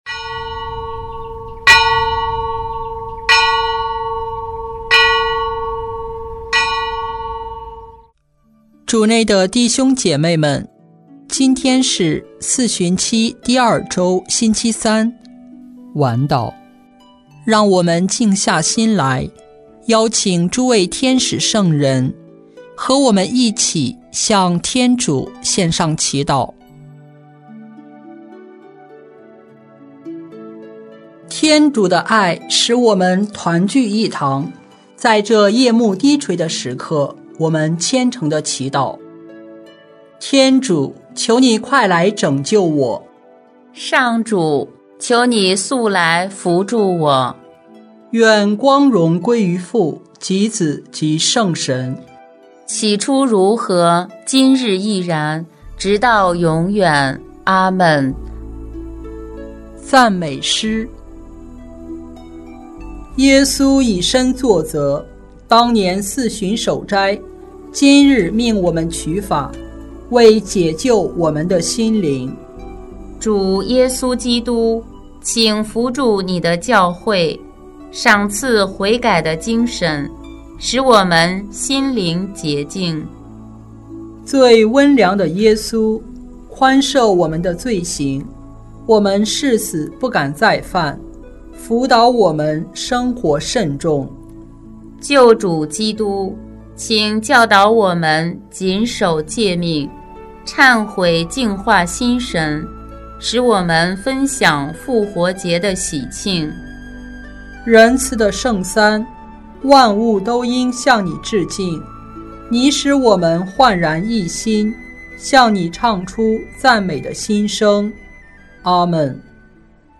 【每日礼赞】|3月4日四旬期第二周星期三晚祷